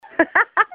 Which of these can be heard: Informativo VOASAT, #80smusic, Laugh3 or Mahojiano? Laugh3